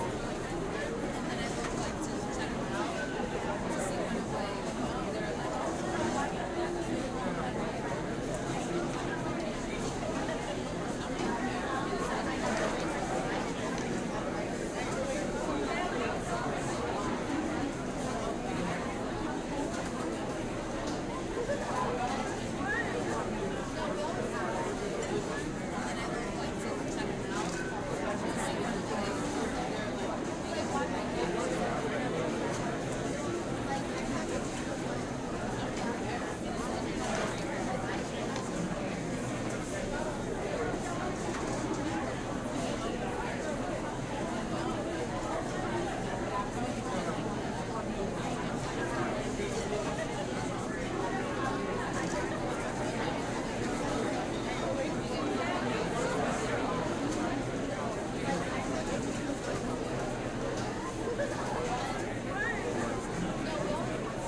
teenageCafeteriaLoop.ogg